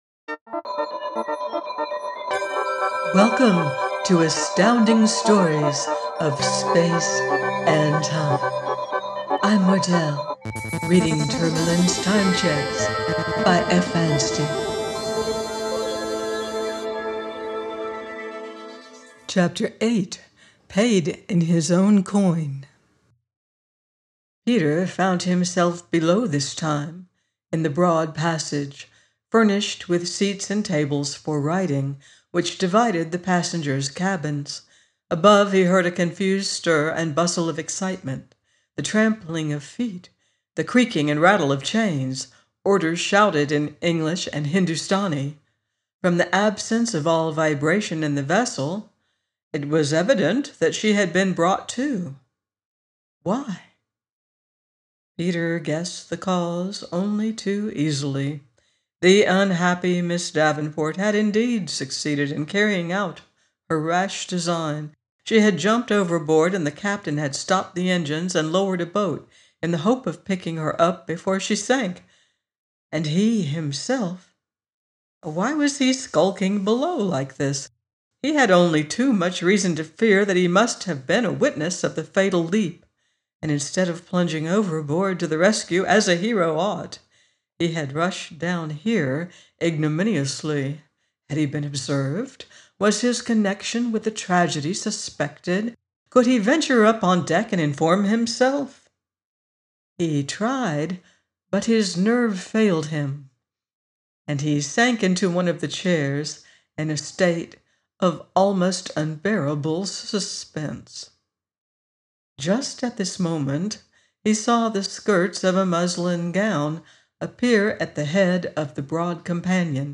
Tourmalin’s Time Cheques – by F. Anstey - audiobook